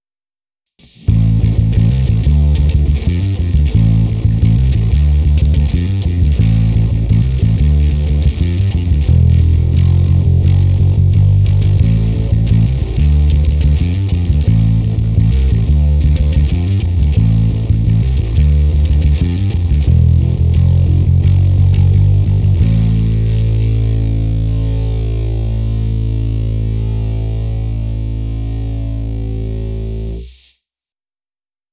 Je hutný, vrčivý, zvonivý, s bohatými středy, s příjemnými výškami a masívními basy.
Tentokrát se mi nechtělo rozštelovávat si můj preamp od Darkglassu, takže jsem pro ukázku toho, jak hraje basa přes aparát, použil simulaci softwarem AmpliTube 4.
Ukázka přes AmpliTube se zkreslením